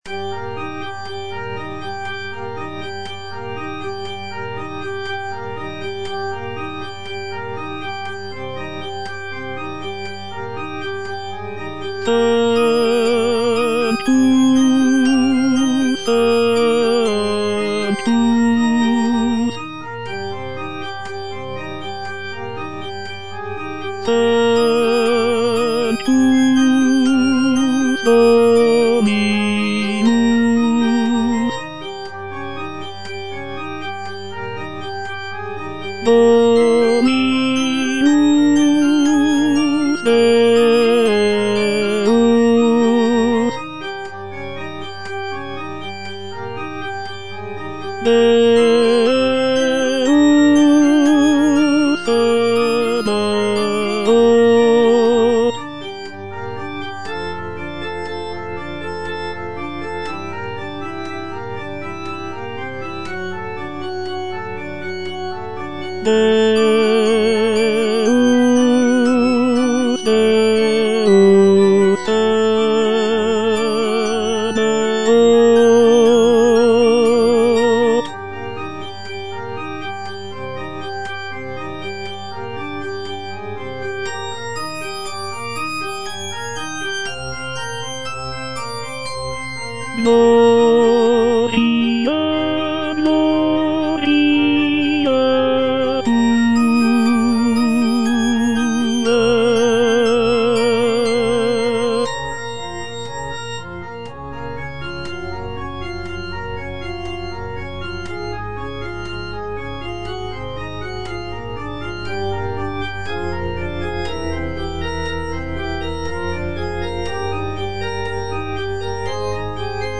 G. FAURÉ - REQUIEM OP.48 (VERSION WITH A SMALLER ORCHESTRA) Sanctus - Tenor (Voice with metronome) Ads stop:
Fauré's Requiem is beloved for its gentle and comforting tone, with a focus on the beauty of the human voice and the peacefulness of the afterlife.